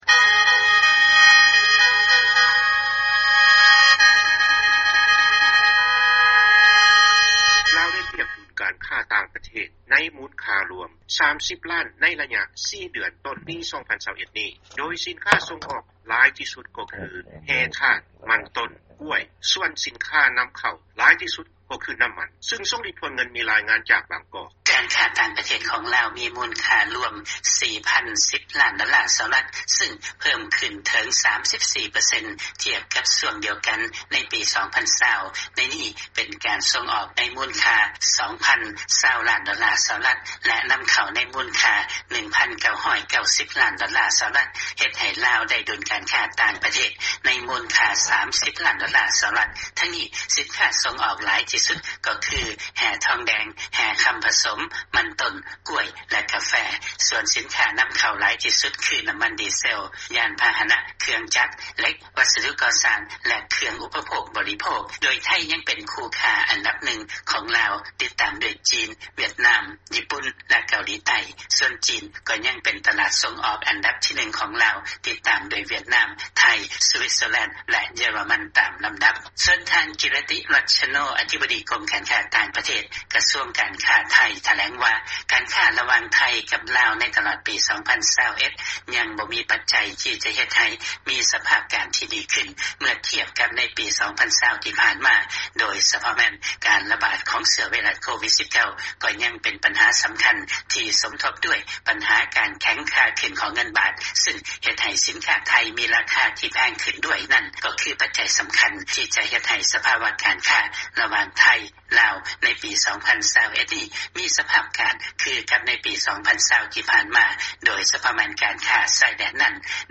ຟັງລາຍງານ ລາວໄດ້ປຽບ ດຸນການຄ້າຕ່າງປະເທດ 30 ລ້ານໂດລາ ໃນໄລຍະ 4 ເດືອນຕົ້ນປີ 2021